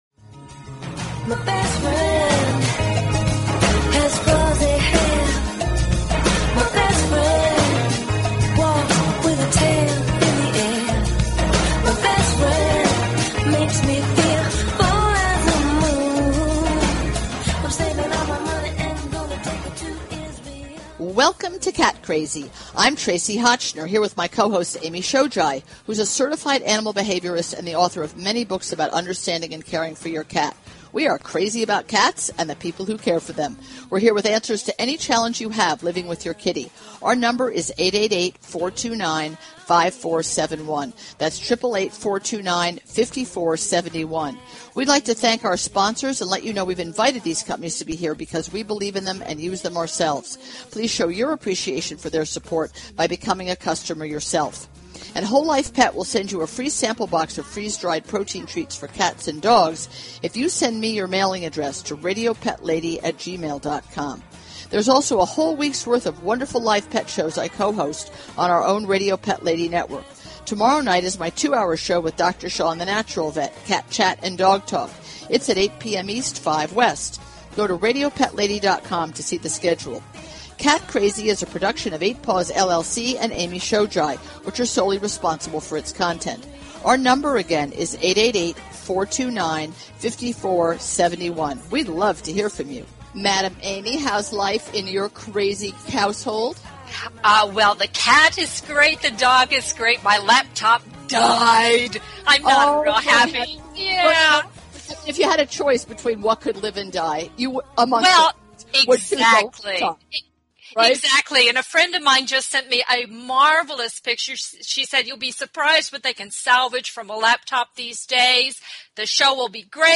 Talk Show Episode, Audio Podcast, Cat_Crazy and Courtesy of BBS Radio on , show guests , about , categorized as